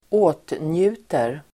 Uttal: [²'å:tnju:ter]